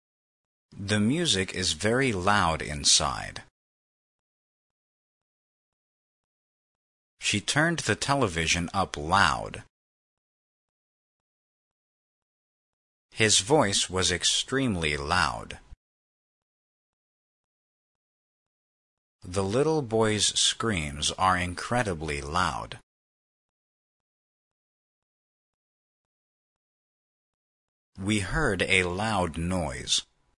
loud-pause.mp3